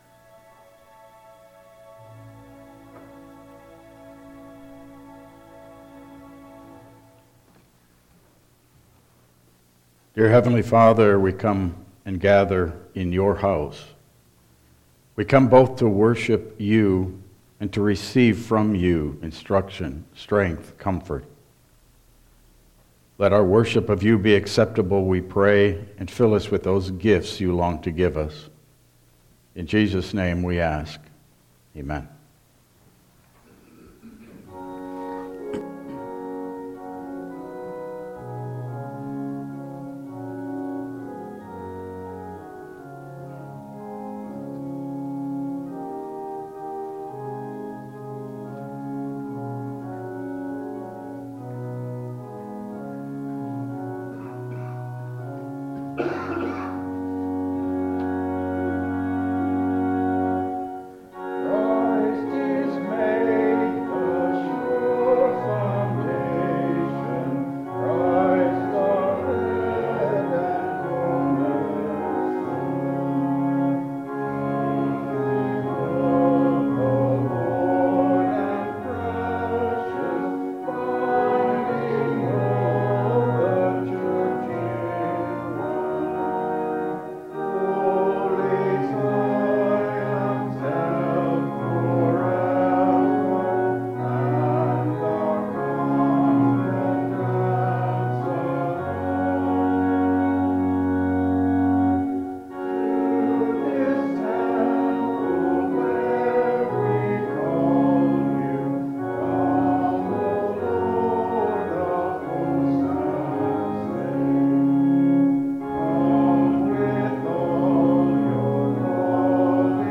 Service Type: Regular Service